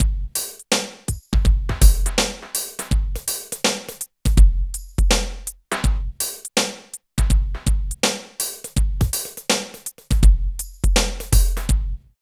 118 LOOP  -R.wav